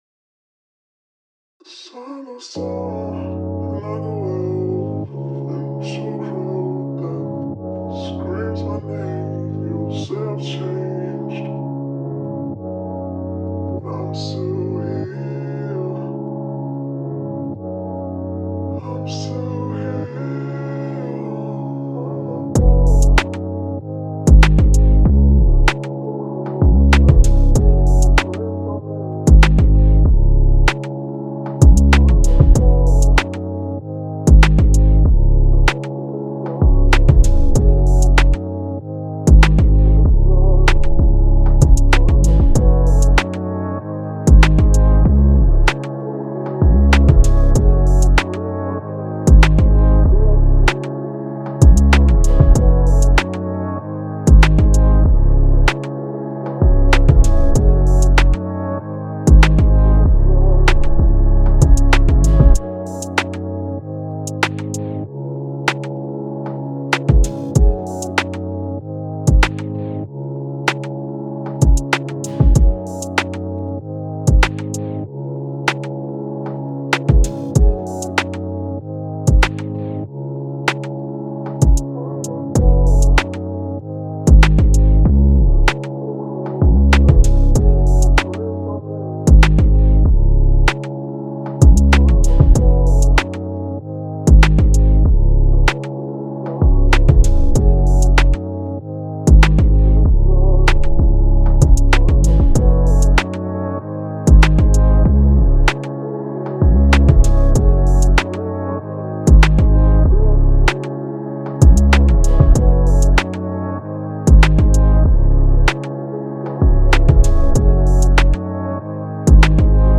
Hip Hop
C min